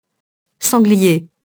sanglier [sɑ̃glije]